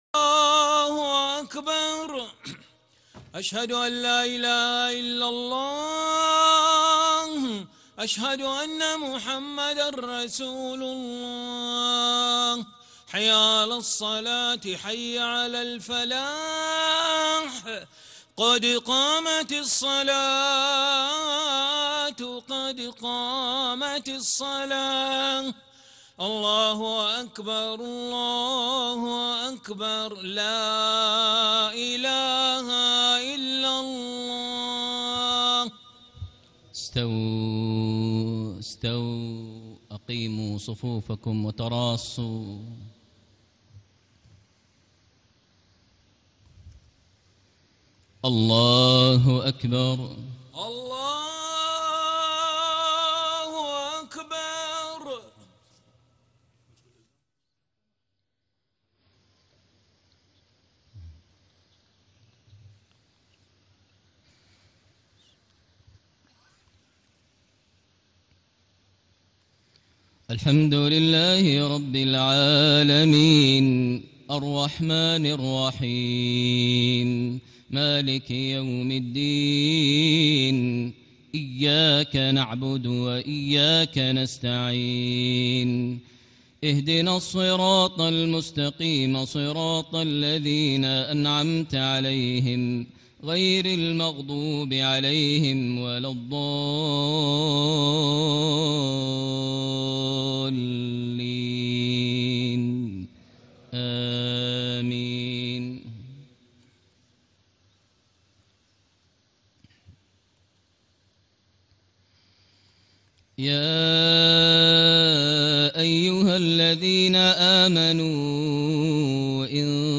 Isha Prayer Surah Muhammed > 1436 H > Prayers - Maher Almuaiqly Recitations